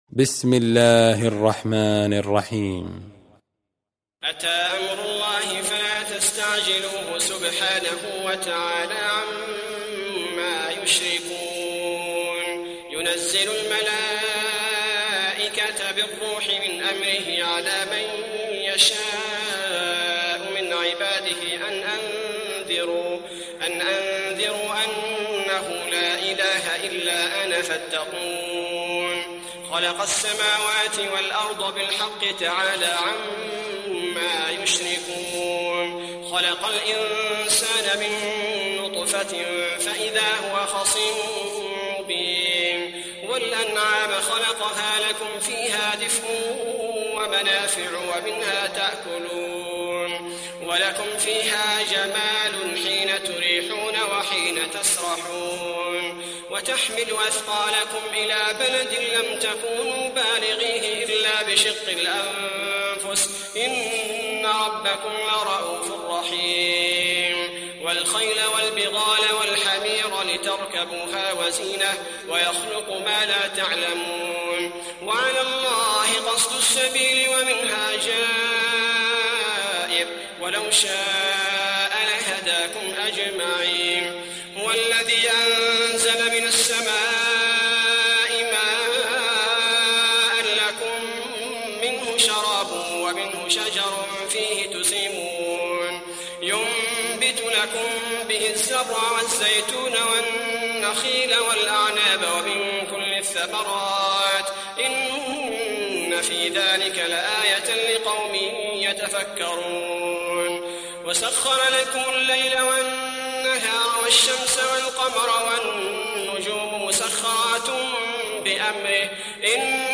Nahl Suresi İndir mp3 Abdul bari al thubaity Riwayat Hafs an Asim, Kurani indirin ve mp3 tam doğrudan bağlantılar dinle